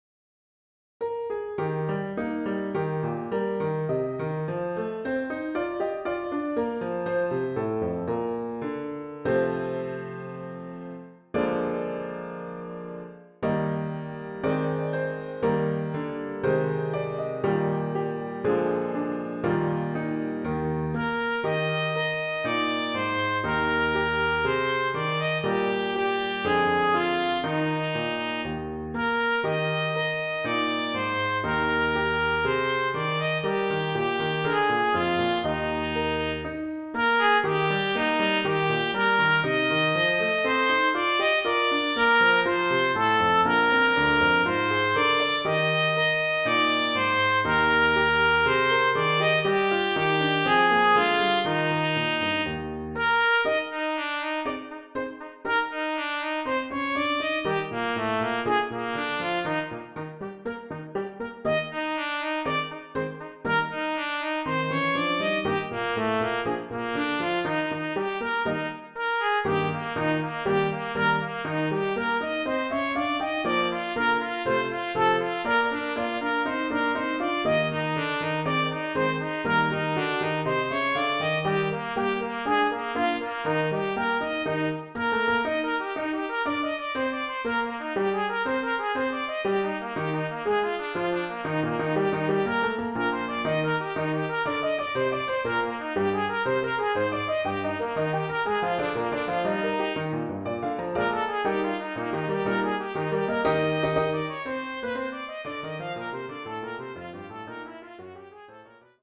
Voicing: Trumpet Solo